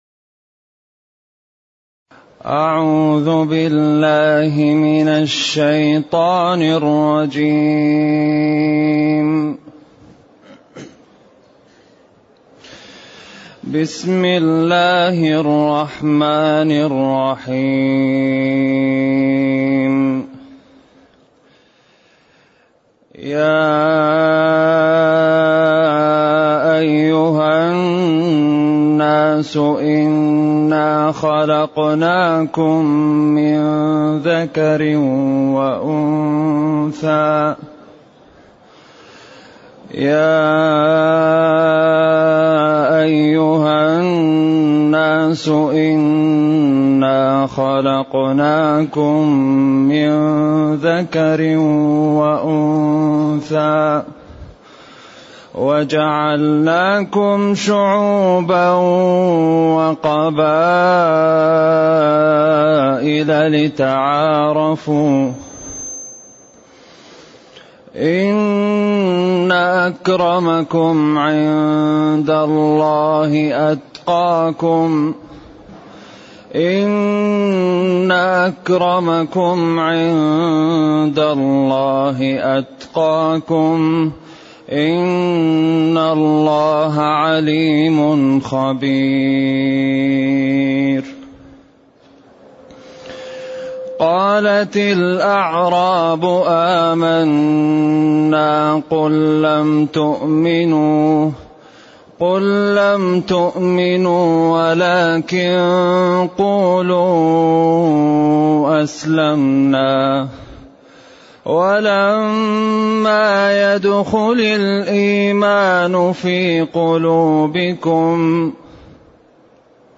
تفسير سورة الحجرات الدرس الأول